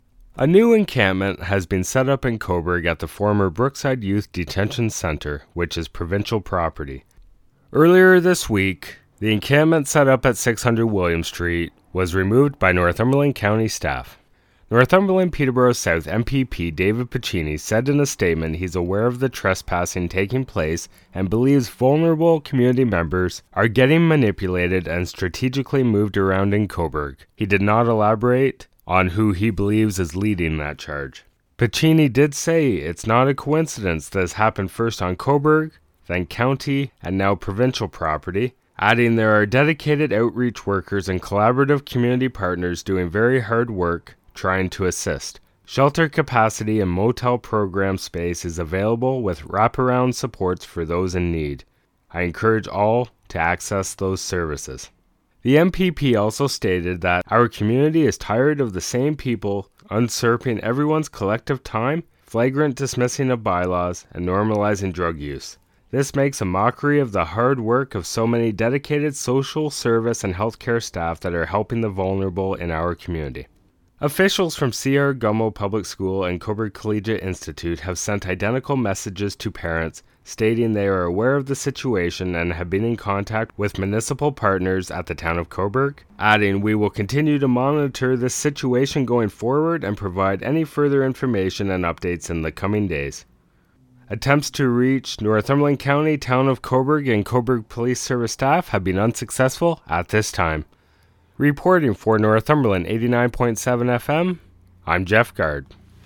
Sept8-Brookside-encampment-report.mp3